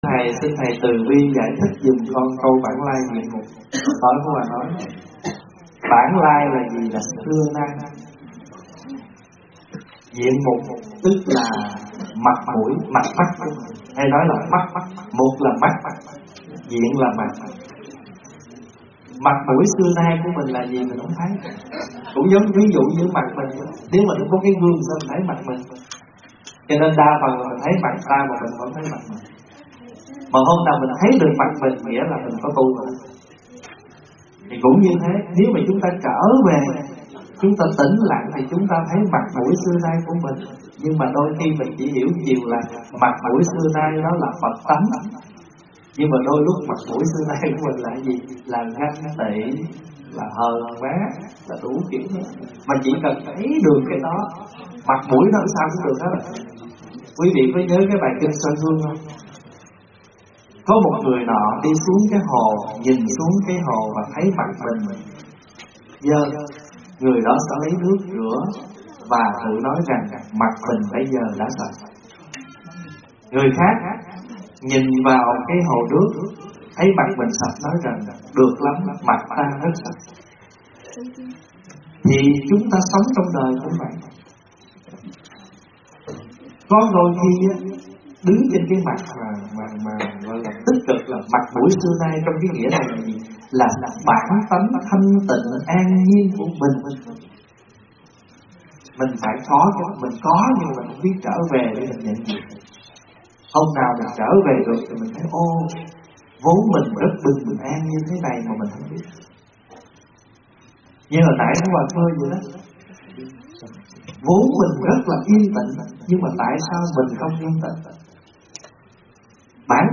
Nghe Mp3 thuyết pháp Ý Nghĩa của "Bản Lai Diện Mục"